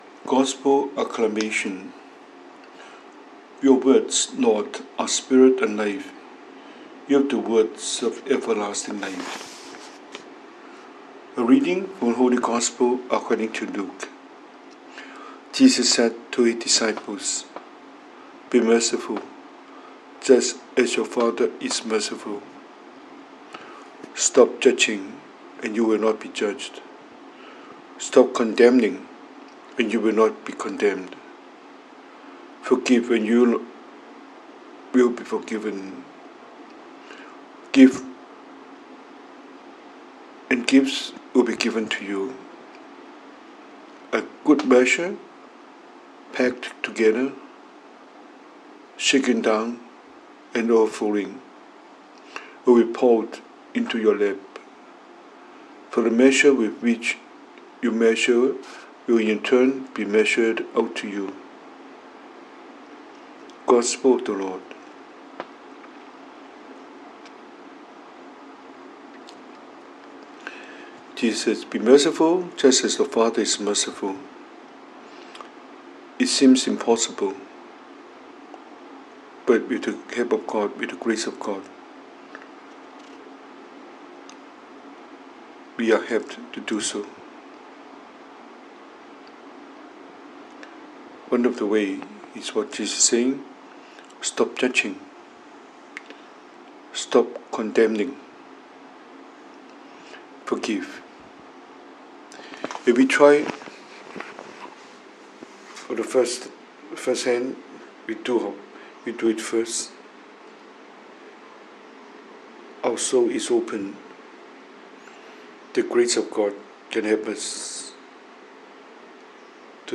中文講道,